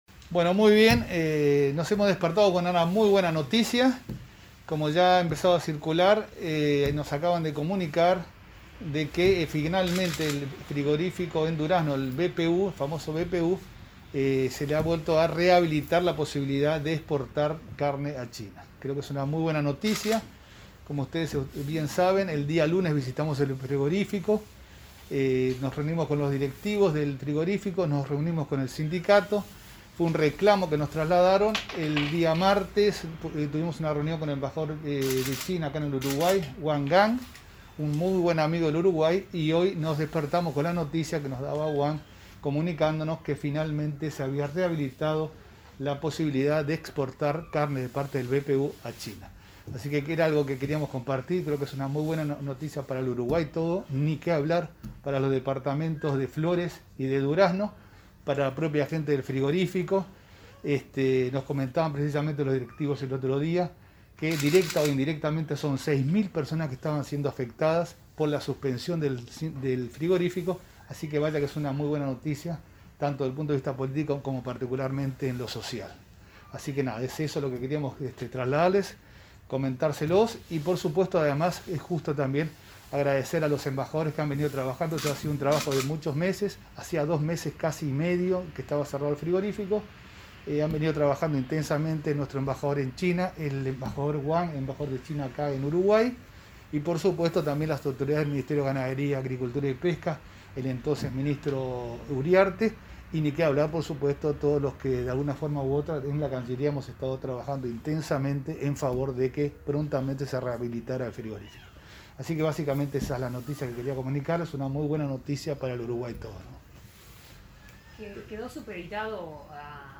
Conferencia de prensa del canciller de la República, Francisco Bustillo
Conferencia de prensa del canciller de la República, Francisco Bustillo 01/07/2021 Compartir Facebook Twitter Copiar enlace WhatsApp LinkedIn Bustillo anunció, este jueves 1 de julio, la reanudación de las exportaciones a China del frigorífico BPU, en conferencia realizada en la sede ministerial.